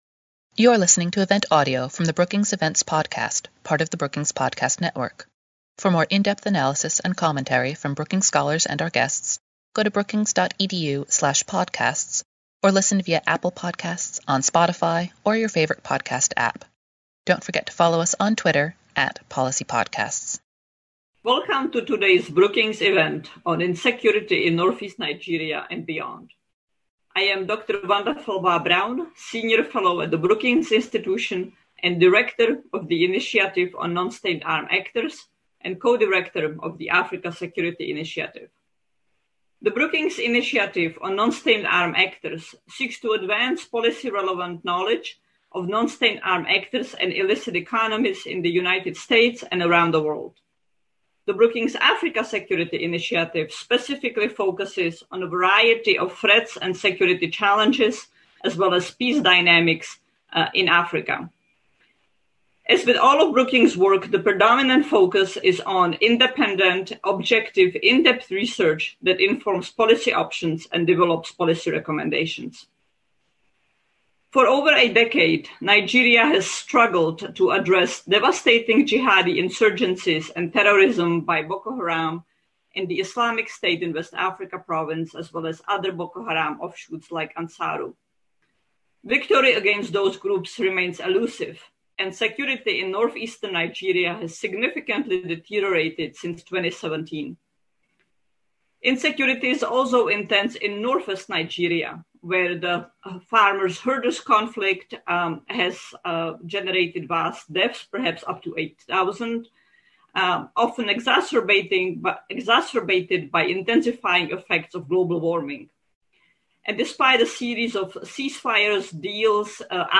On December 7, the Initiative on Nonstate Armed Actors and the Africa Security Initiative at Brookings held a panel discussion to explore these complex and overlapping issues. After their remarks, panelists took questions from the audience.